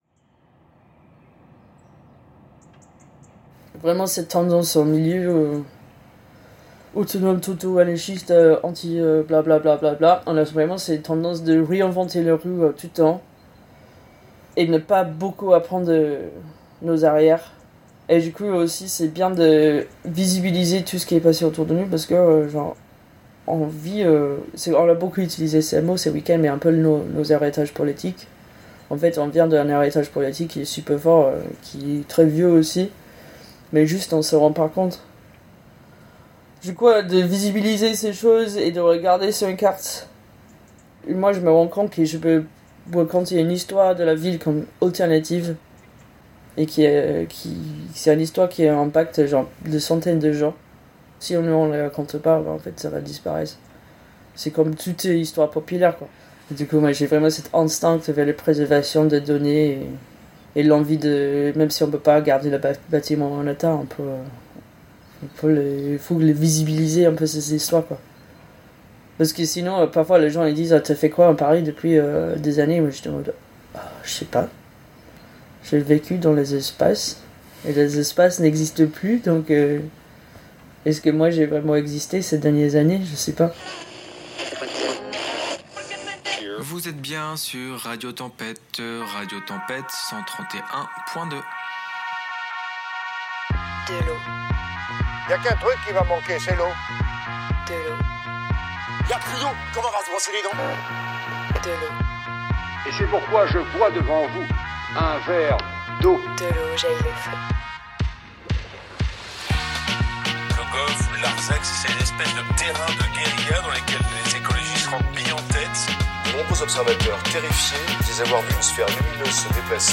Après avoir parcouru et soutenu les différents squats du 93, aujourd’hui expulsés, notamment la Malvassé, le Bathyscaphe ou encore la Baudrière, nous déposons nos valises dans l’un des derniers squats d’organisation politique et de vie d’Ile-de-France : la Kunda à Vitry-sur-Seine.